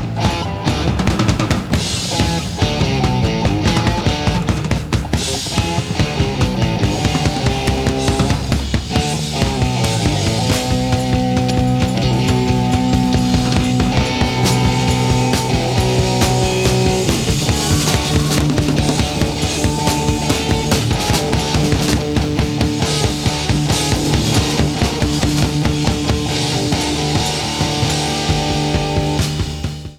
Format/Rating/Source: CD - B+ - Soundboard